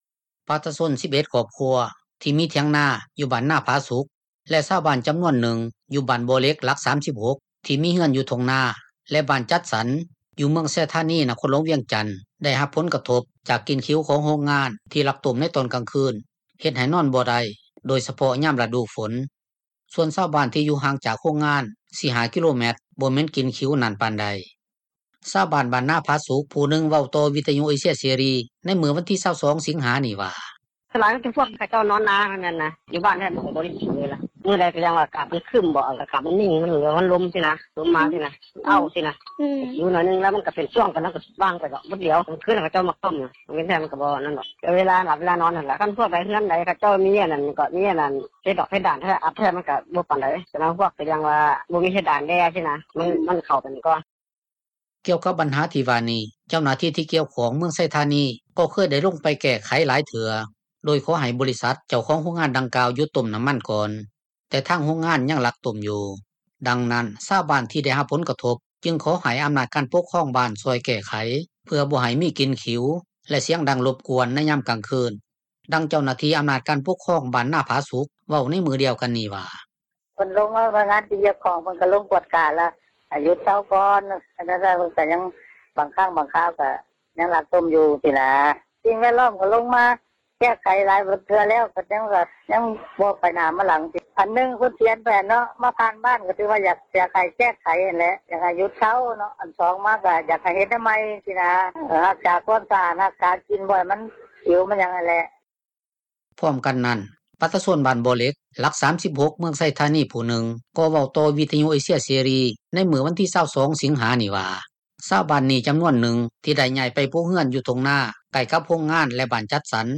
ຊາວບ້ານ ບ້ານນາຜາສຸກ ຜູ້ນຶ່ງເວົ້າຕໍ່ວິທຍຸເອເຊັຽເສຣີໃນມື້ວັນທີ 22 ສິງຫານີ້ວ່າ:
ດັ່ງເຈົ້າໜ້າທີ່ ທີ່ກ່ຽວຂ້ອງເມືອງນີ້ເວົ້າຕໍ່ ວິທຍຸເອເຊັຽເສຣີ ໃນມື້ວັນທີ 22 ສິງຫານີ້ວ່າ: